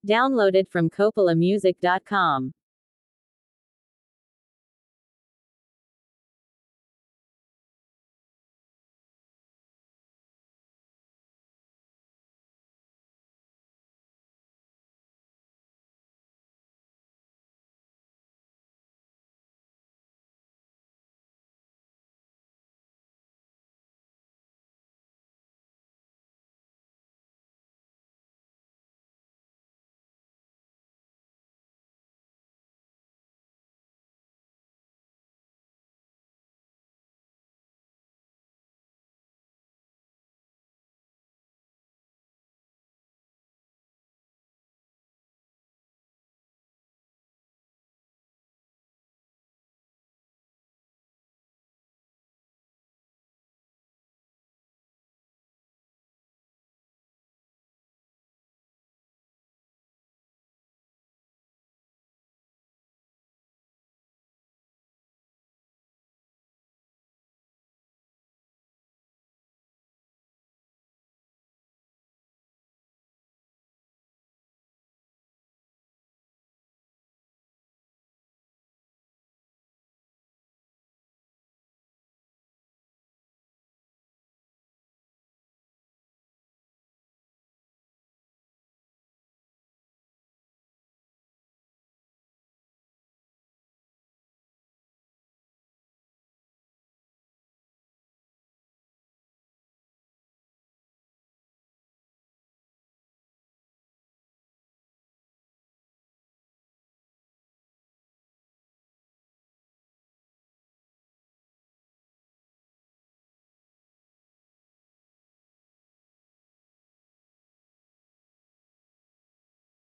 Zambian Music
inspiring and emotionally uplifting song